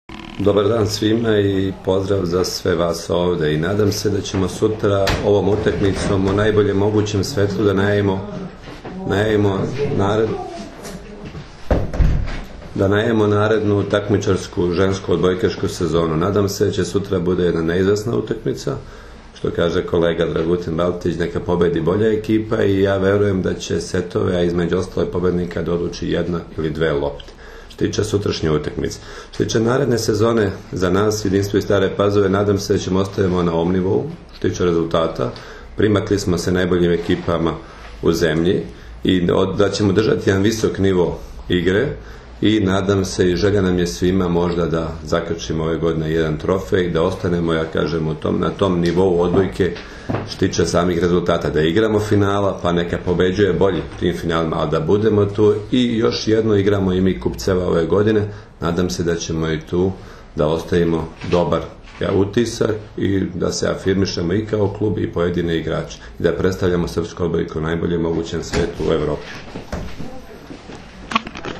U prostorijama Odbojkaškog saveza Srbije danas je održana konferencija za novinare povodom utakmice IV Super Kupa Srbije 2016. u konkurenciji odbojkašica, koja će se odigrati sutra (četvrtak, 13. oktobar) od 18,00 časova u dvorani SC “Vizura” u Beogradu, između Vizure i Jedinstva iz Stare Pazove, uz direktan prenos na RTS 2.
IZJAVA